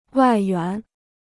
外援 (wài yuán): foreign aid; external aid.